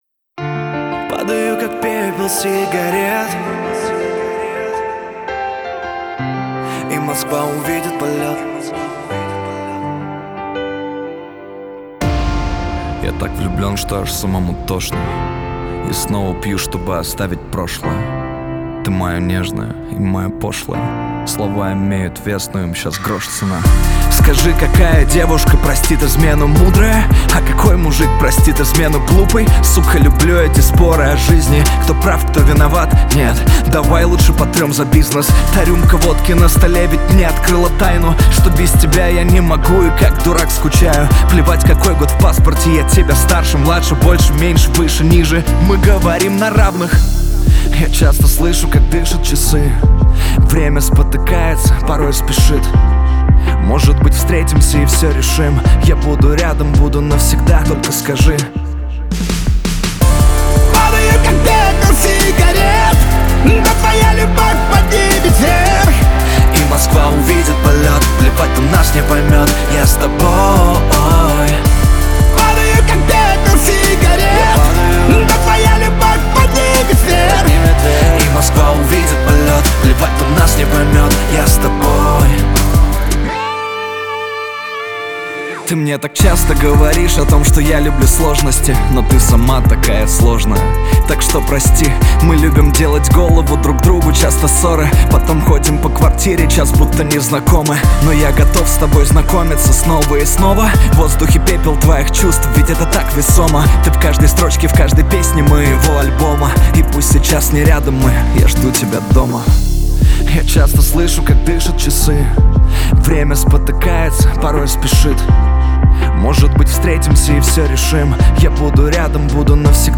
Качество: 320 kbps, stereo
Поп музыка, Русские поп песни